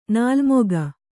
♪ nālmoga